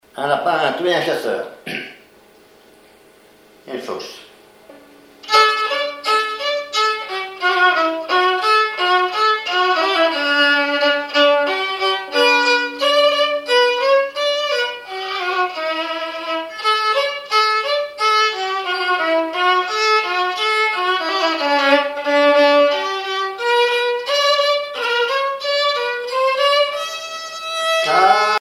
violoneux, violon,
Genre strophique
Catégorie Pièce musicale inédite